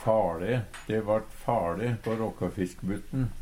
Høyr på uttala Ordklasse: Substantiv hankjønn Attende til søk